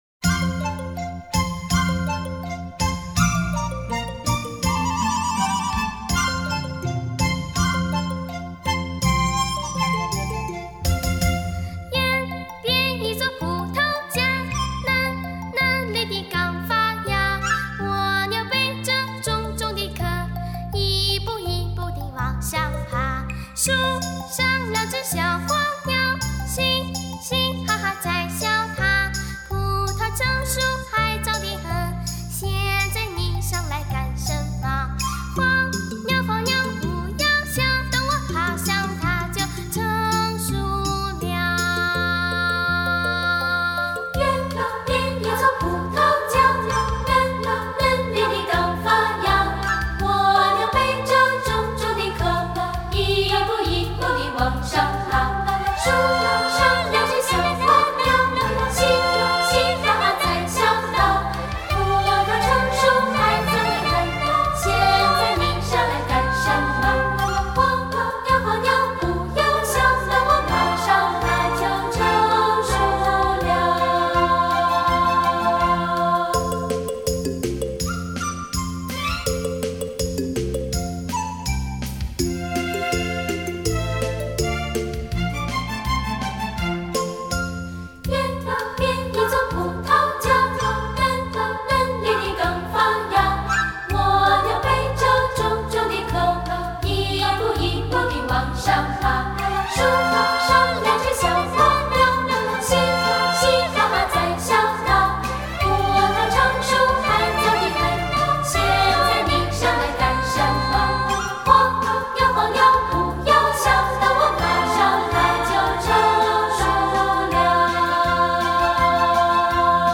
本专辑收录多首经典教会圣诗以及令人怀念的民歌； 幸福温暖的合声，满足喜爱音乐的耳朵，更温暖您的心，温暖整个世界的心。